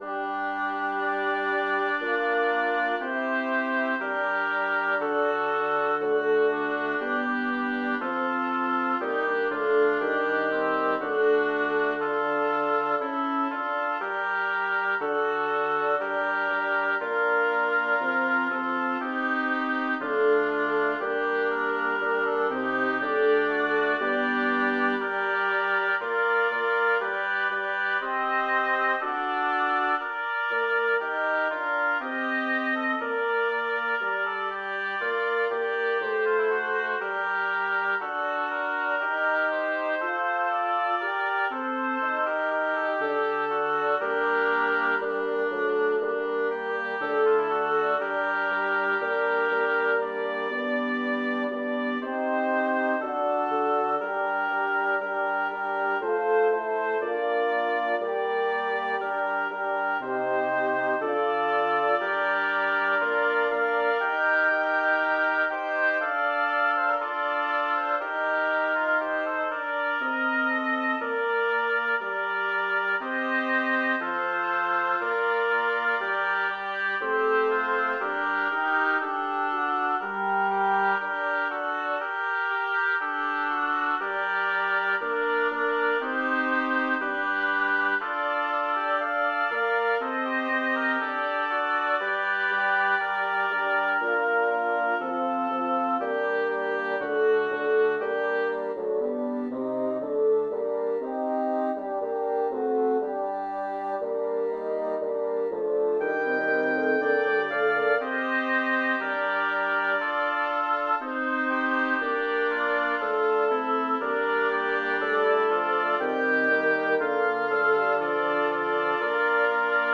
Number of voices: 6vv Voicing: SSATTB Genre: Secular, Madrigal
Language: Italian Instruments: A cappella